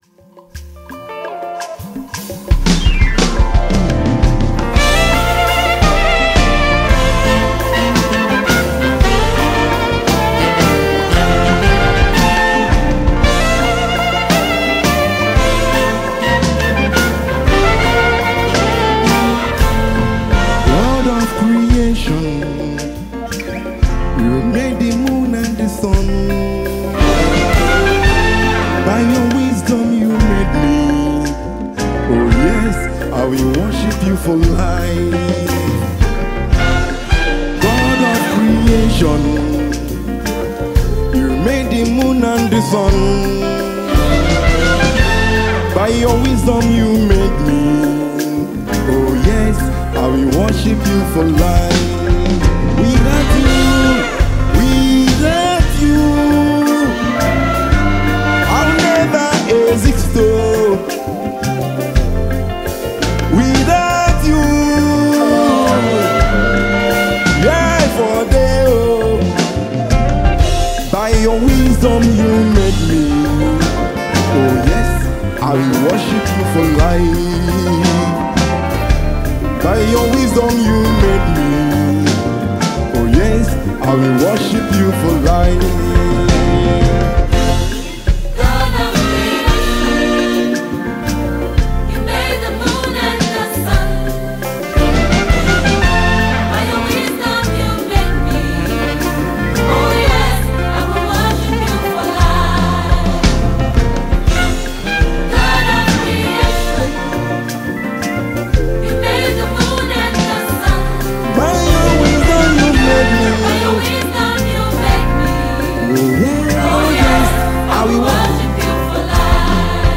gospel music
songwriter and saxophonist.
music comes heavily blended in traditional African rhythms